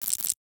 NOTIFICATION_Subtle_13_mono.wav